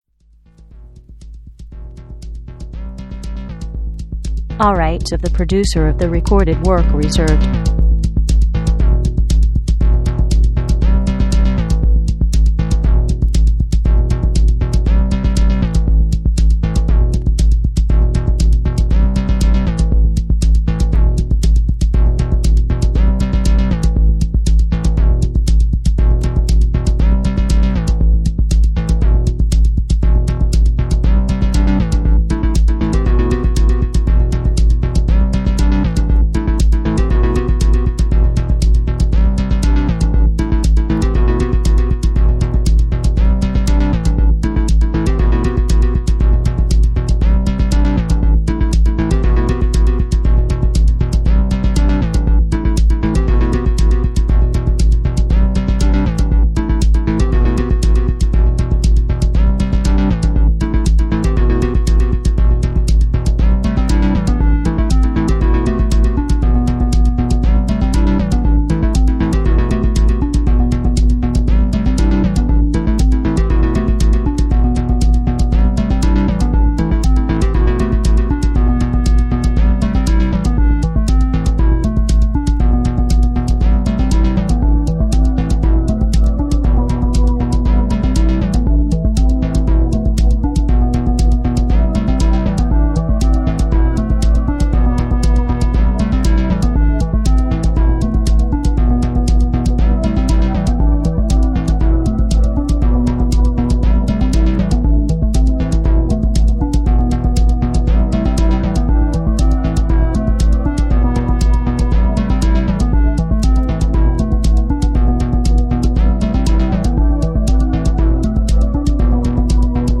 Finest dark and sinister electro with that extra deepness.